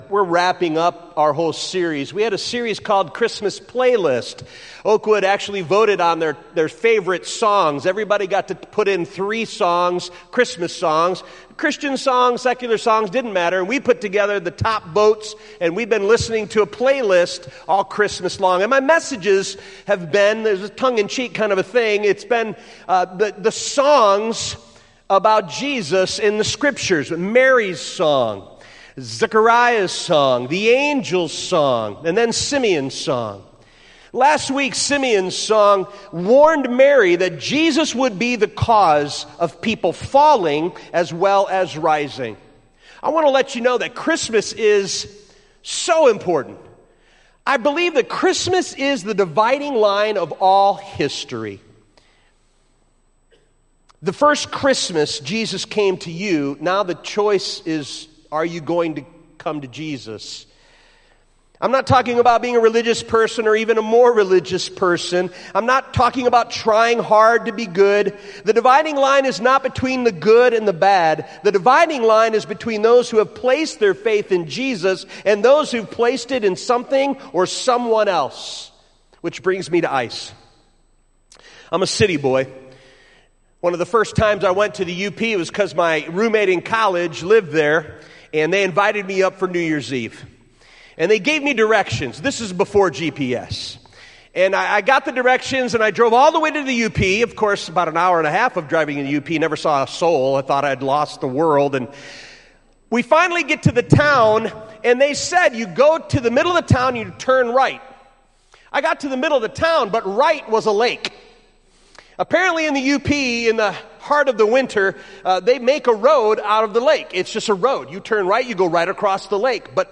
Oakwood Community Church concludes the ‘Christmas Playlist' series with a candlelight service titled, "Songs of the Savior". This service includes children's bells, a wonderful choir, beautiful worship music, inspiring videos and a salvation message